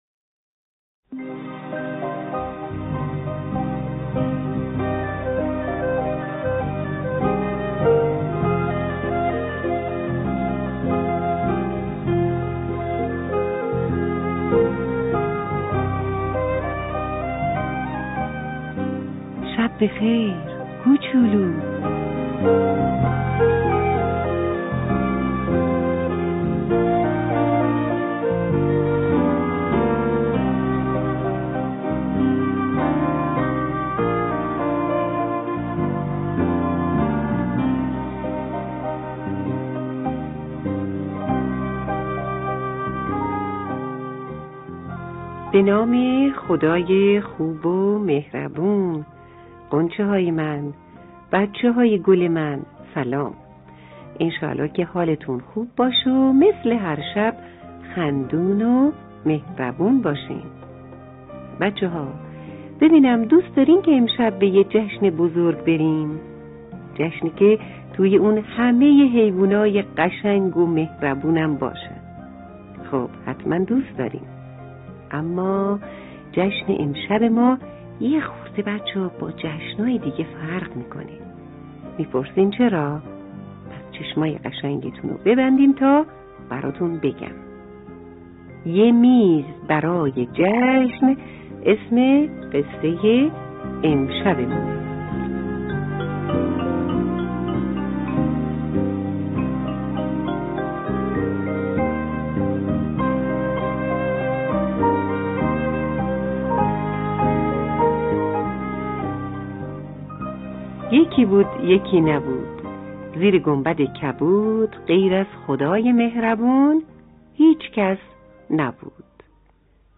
داستان صوتی لالایی؛ یه میز برای جشن